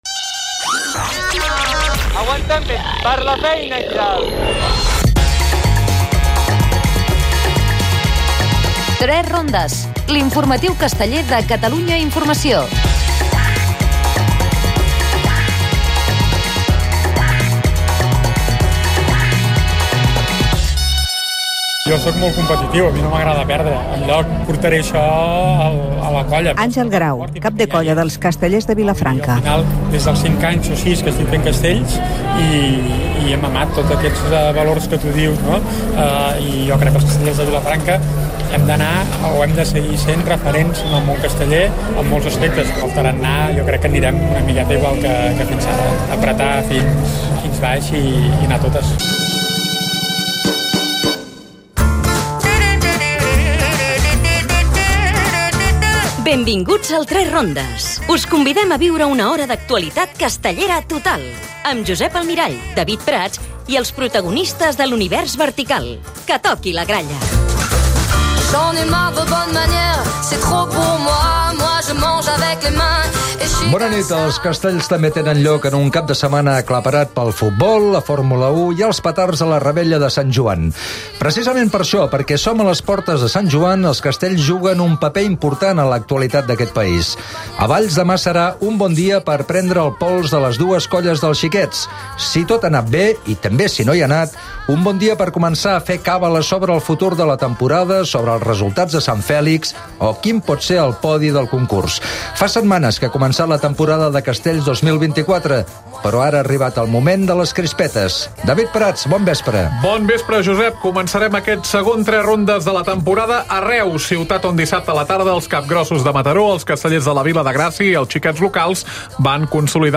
Al "3 rondes" hem estat a les diades de Sant Pere de Reus, prvies de Sant Joan a Valls, Tarragona, el Pla de la Seu i la festa major de Terrassa. Tertlia
Entrevista